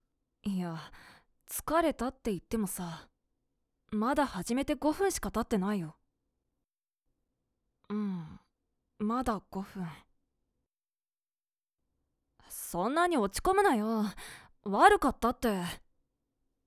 男の子.wav